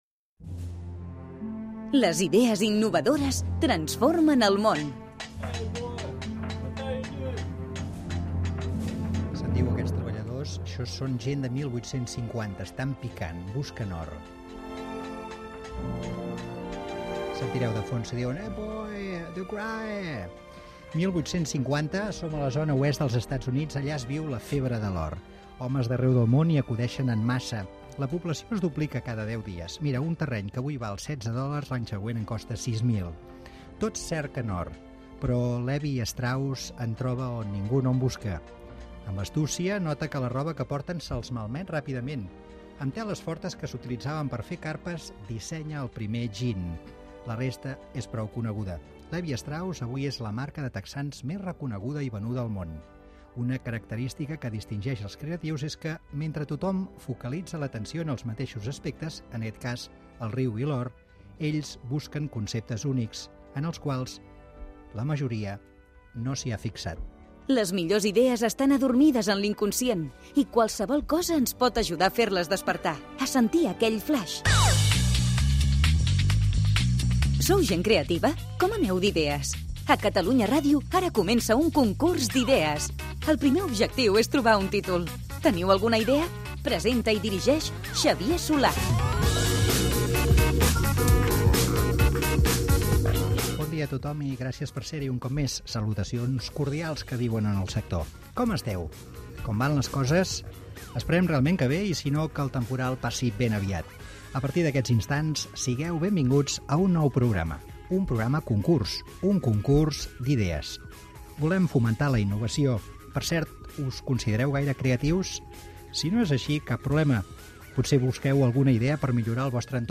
Es busca un títol per al programa. Gènere radiofònic Entreteniment